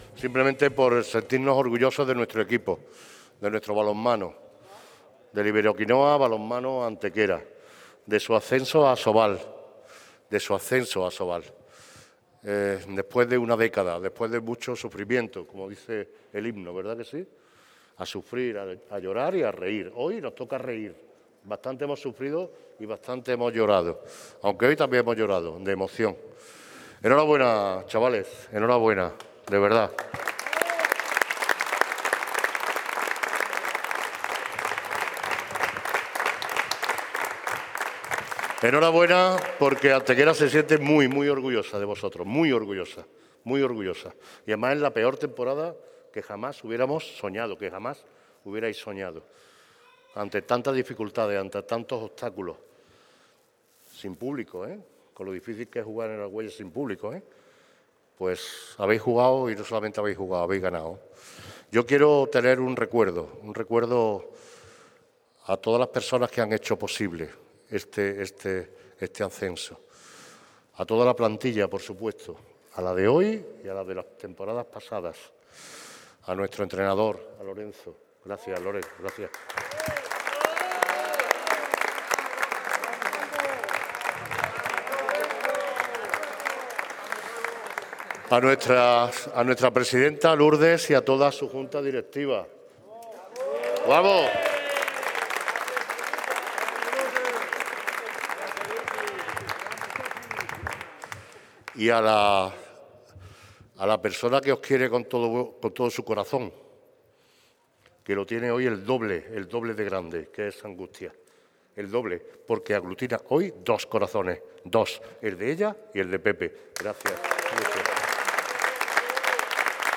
El simbólico final de fiesta acababa también en palabras del propio Manolo Barón, que se metió de lleno en el papel de aficionado, cantando a los sones de "el año que viene, jugamos en ASOBAL", momento en el que los propios jugadores auparon al Alcalde.
Cortes de voz